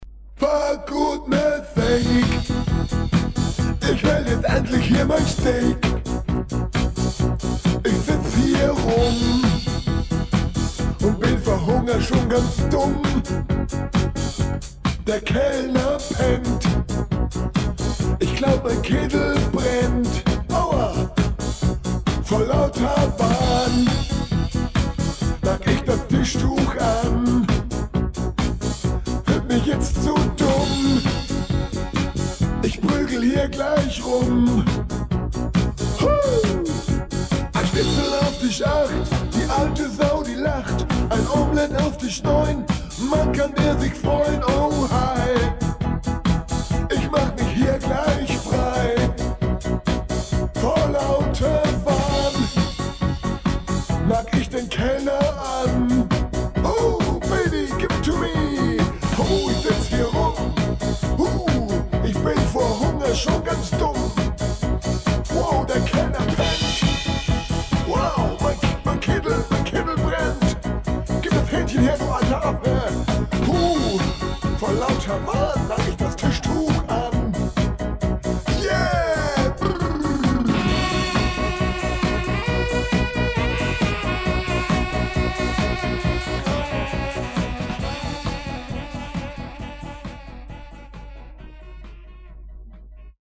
aus den SWF3 Sendungen vor über 40 Jahren aufgenommene Mitschnitte, digitalisiert und hier nun veröffentlicht.
Etwa alle 2 - 3 Wochen nach dem Samstag Mittagessen schaltete man das Radio an und nahm diese Sendungen auf MC auf.